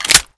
/cstrike/sound/weapons/new_weapons/deagle_tiger/
Deagle_SlideBack.wav